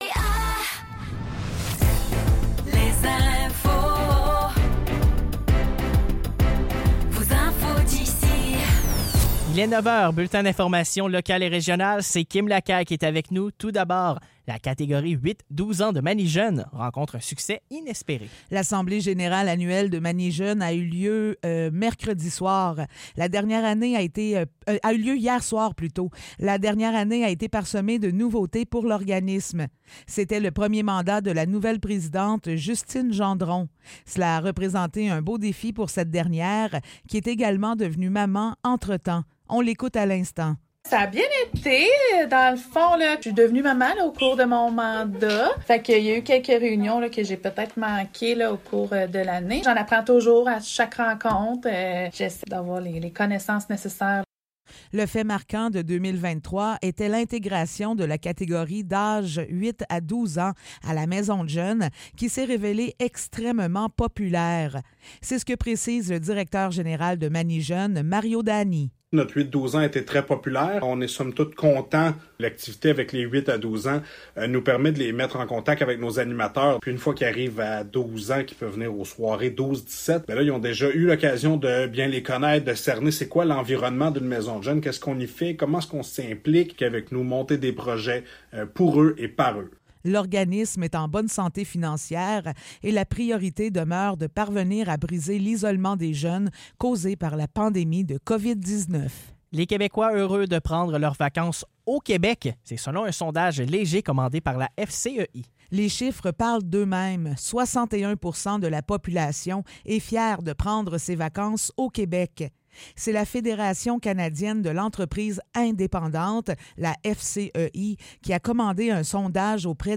Nouvelles locales - 12 juillet 2024 - 9 h